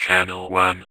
VVE1 Vocoder Phrases 07.wav